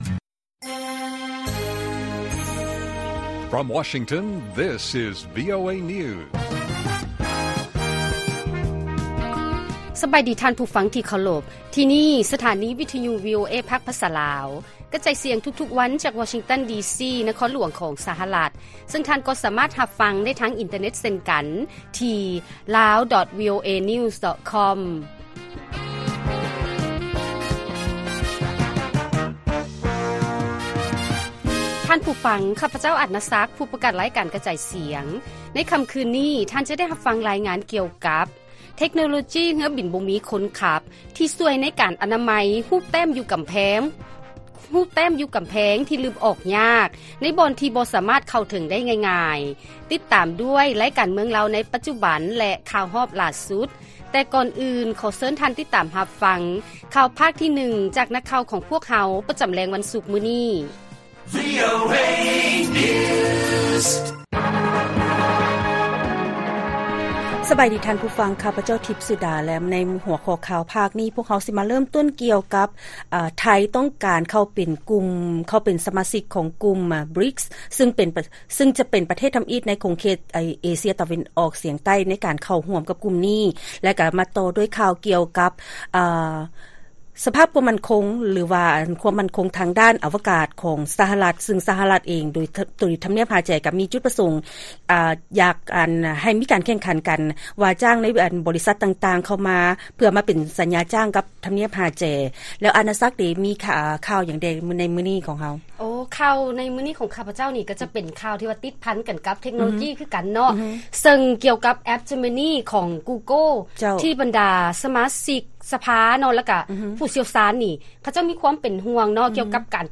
ລາຍການກະຈາຍສຽງຂອງວີໂອເອ ລາວ: ເທັກໂນໂລຈີເຮືອບິນບໍ່ມີຄົນຂັບ ທີ່ຊ່ວຍໃນການອະະນາໄມ ຮູບແຕ້ມຢູ່ກຳແພງ ທີ່ລຶບອອກຍາກ ໃນບ່ອນທີ່ບໍ່ສາມາດເຂົ້າເຖິງໄດ້ງ່າຍ